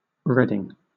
Reading ([ˈɹɛdɪŋ];